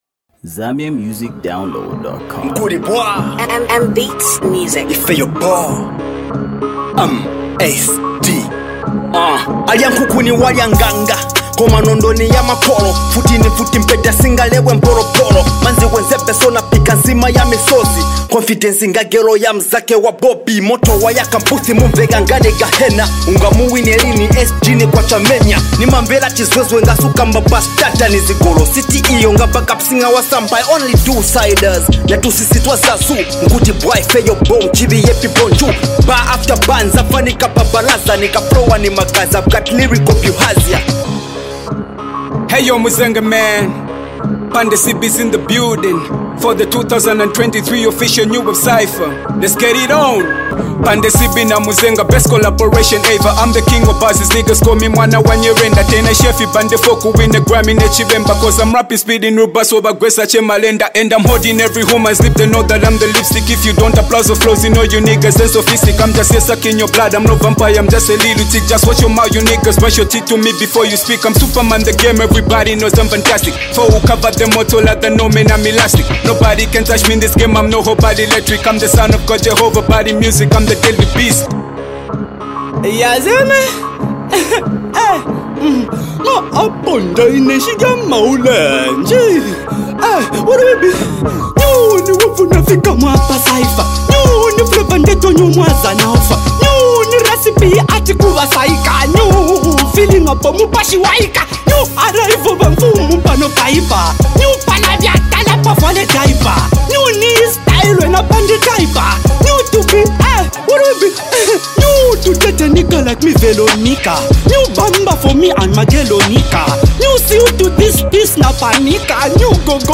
Renowned in the realm of Zambian HipHop music production